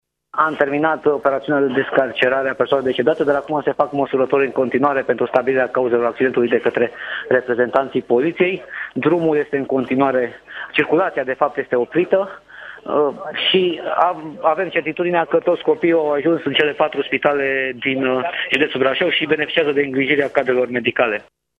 Prefectul județului Brașov, Ciprian Băncilă, a precizat pentru Digi24, că circulația în zonă este în continuare blocată și se menține planul roșu de intervenție: